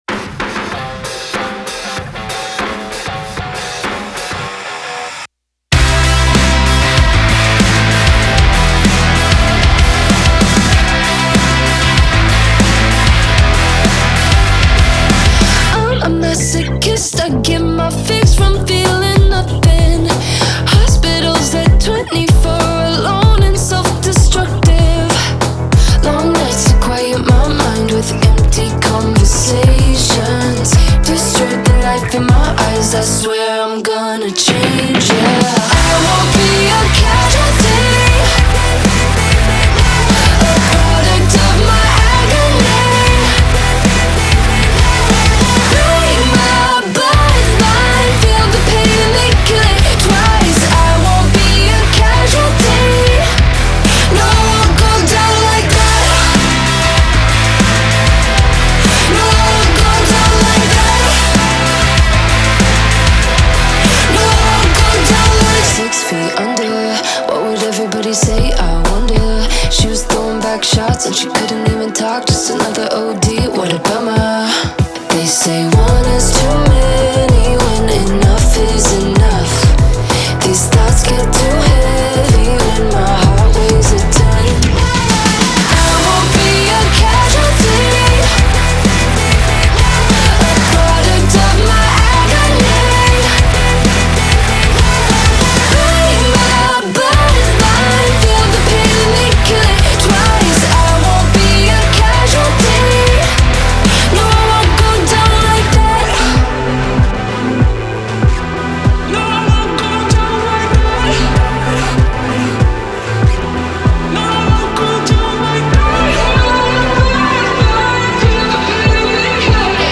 BPM96-96
Audio QualityPerfect (High Quality)
Full Length Song (not arcade length cut)